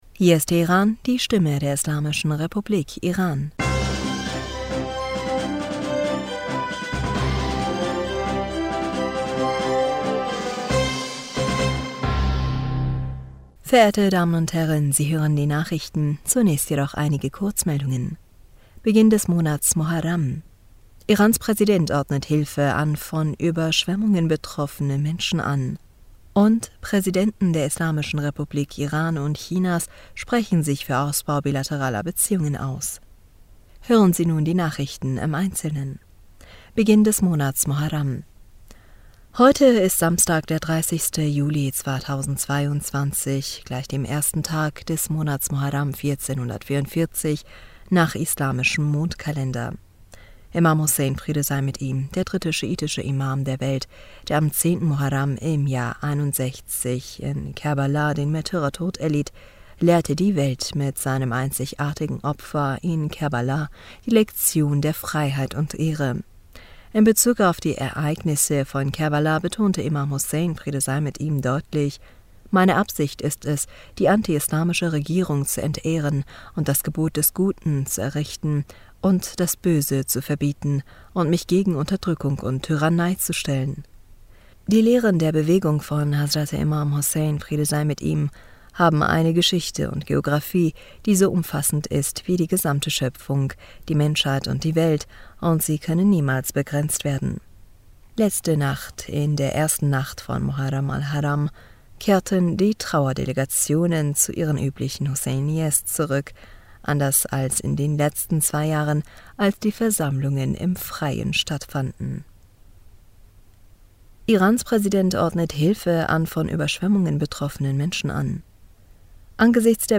Nachrichten vom 30. Juli 2022
Die Nachrichten von Samstag, dem 30. Juli 2022